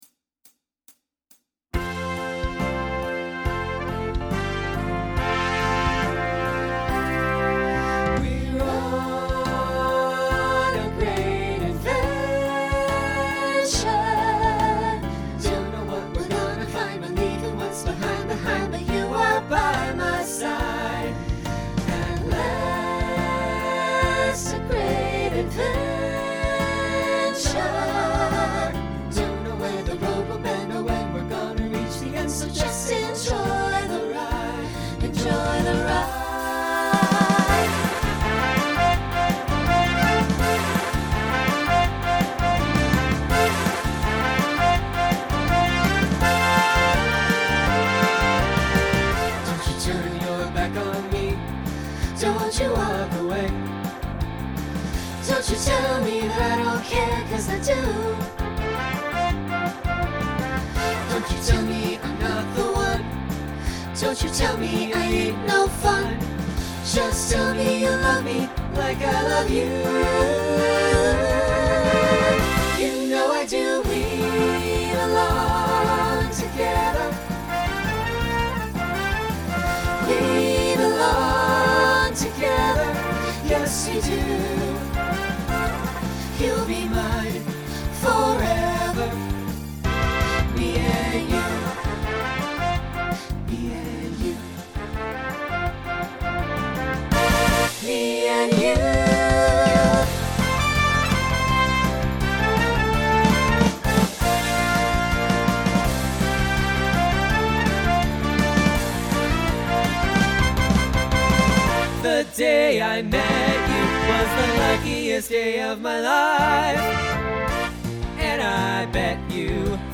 Genre Broadway/Film Instrumental combo
Voicing SAB